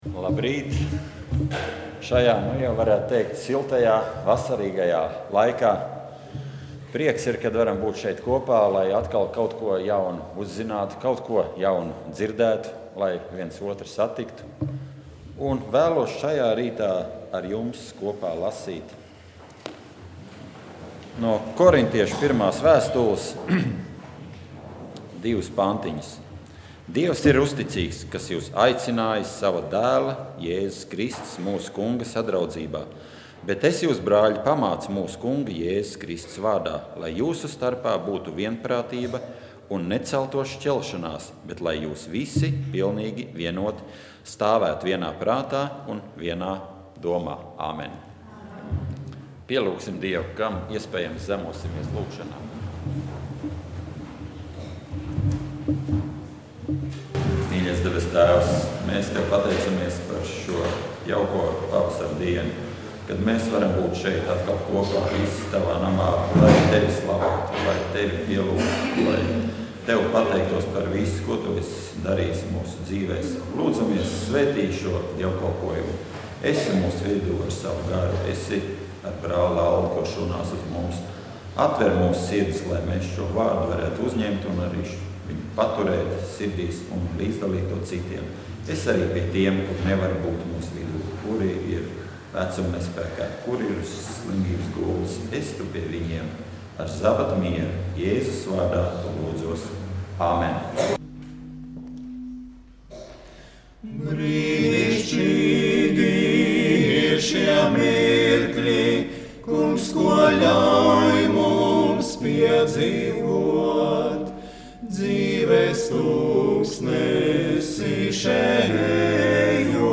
Dievkalpojums 05.05.2012: Klausīties
Svētrunas